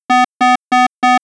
beepsound.wav